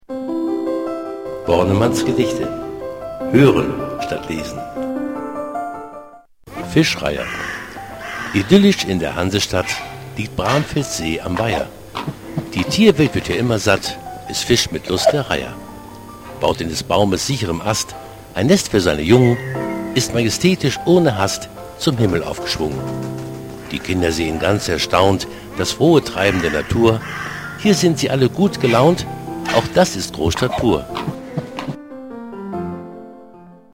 Vorlesen